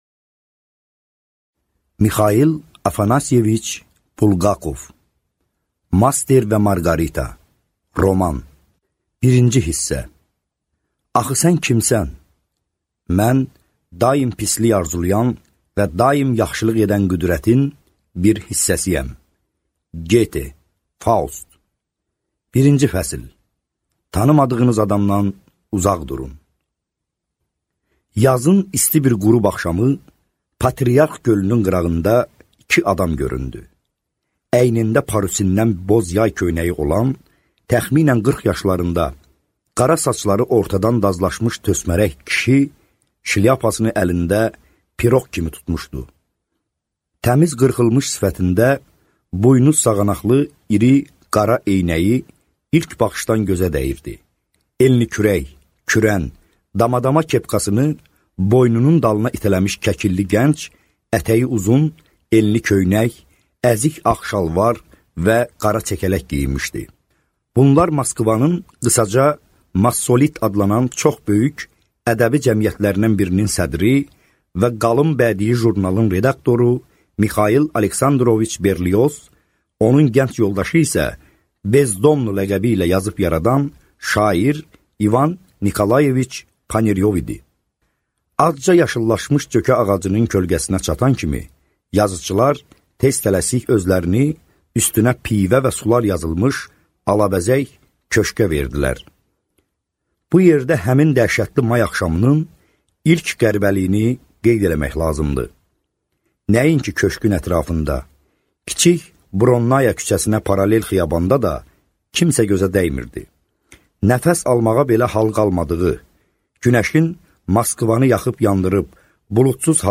Аудиокнига Master və Marqarita | Библиотека аудиокниг